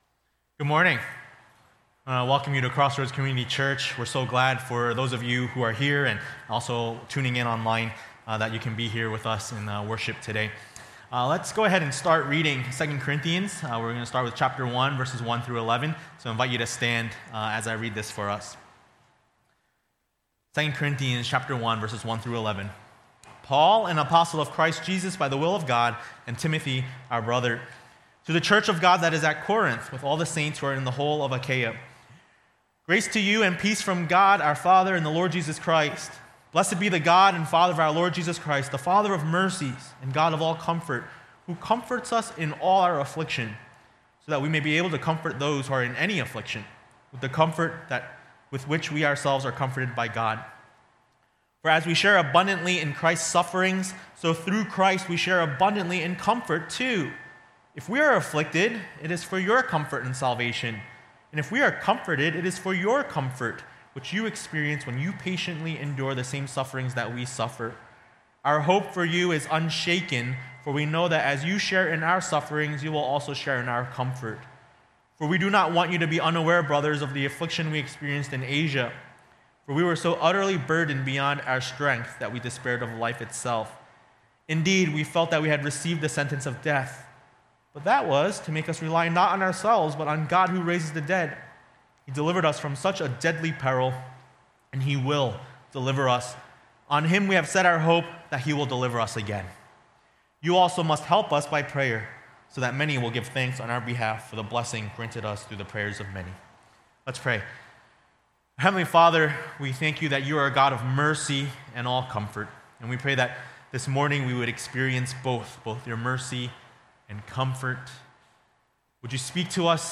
A message from the series "2 Corinthians ."